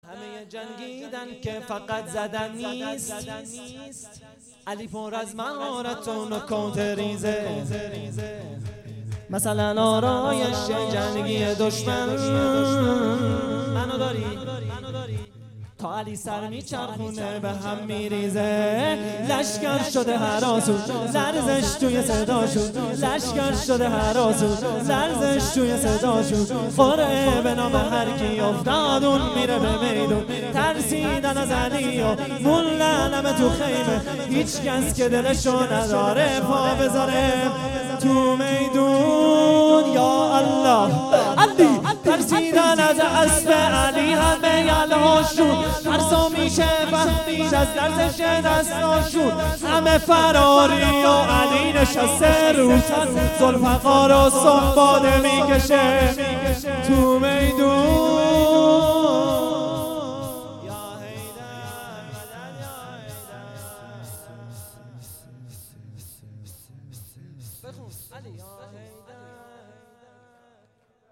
شور طوفانی حضرت امیر المومنین اجرا شده در هیئت الزهرا